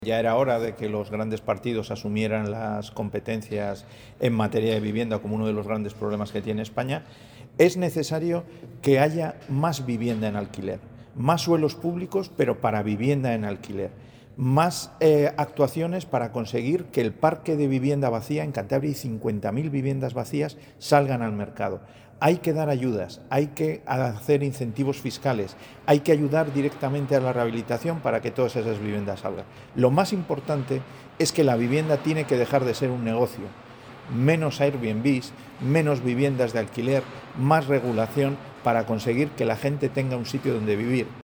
Ver declaraciones de Pedro Hernando, portavoz parlamentario del Partido Regionalista de Cantabria.